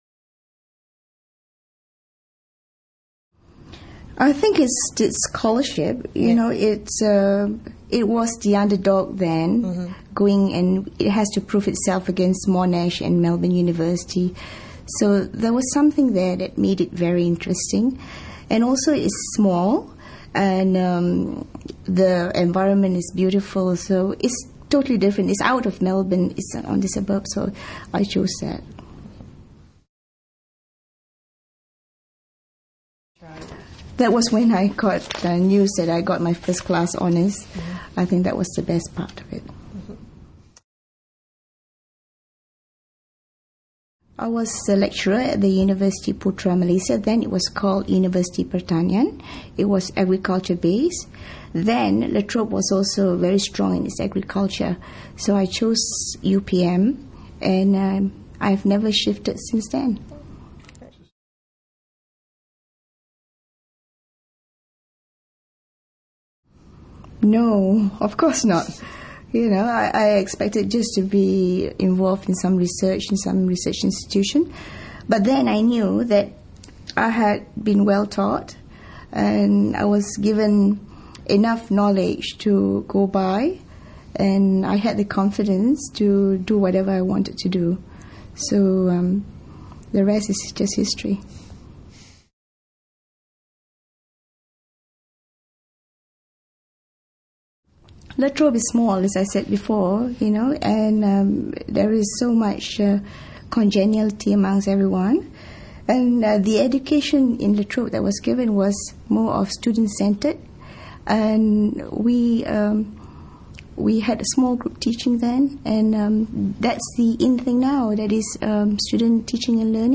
We were fortunate to have the opportunity to interview some of the Distinguished Alumni Awards winners about their time at La Trobe University.